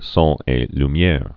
(sôn ā lüm-yâr)